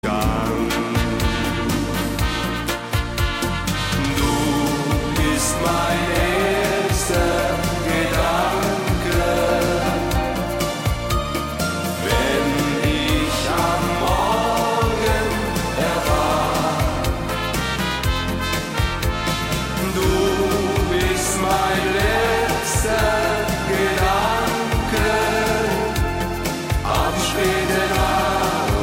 Besetzung: Blasorchester
Tonart: Es-Dur